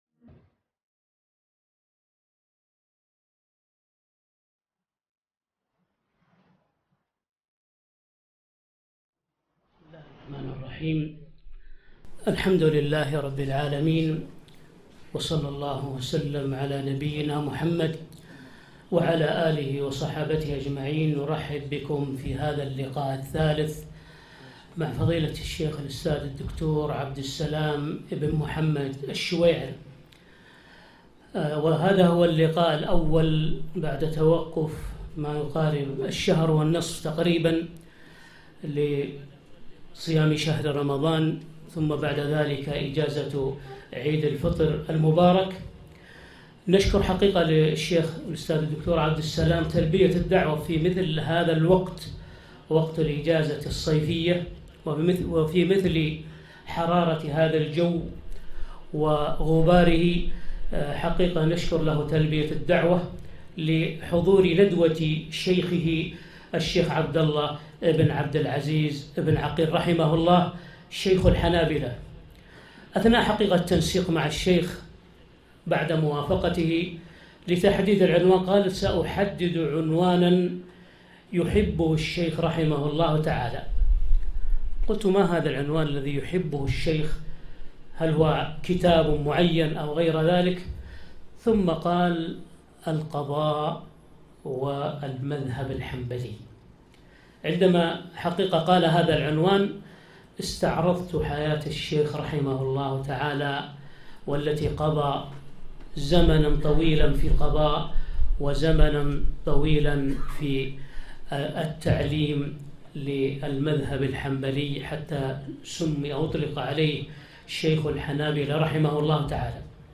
محاضرة - لقضاء والمذهب الحنبلي - دروس الكويت